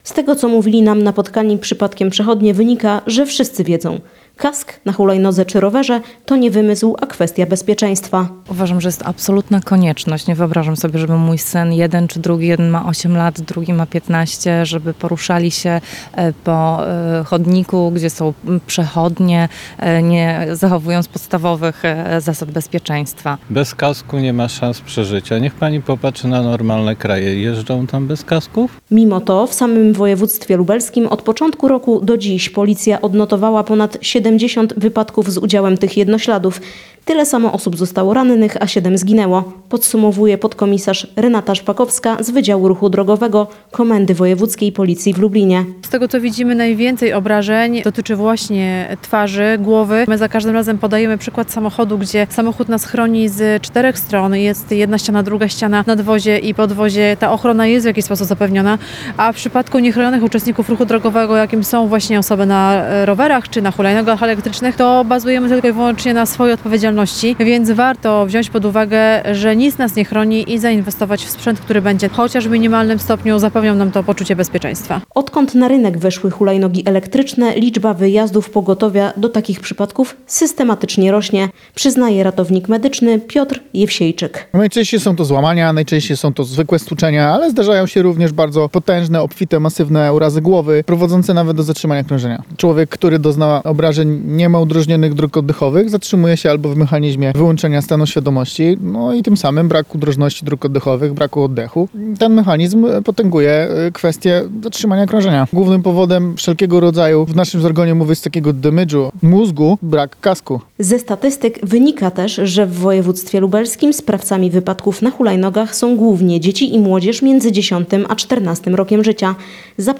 Z tego, co mówili nam napotkani przechodnie, wynika, że wszyscy wiedzą – kask na hulajnodze czy rowerze to nie wymysł, a kwestia bezpieczeństwa.